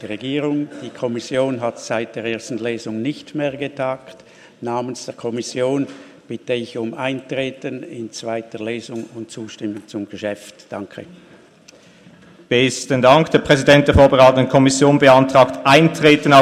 Session des Kantonsrates vom 19. und 20. Februar 2018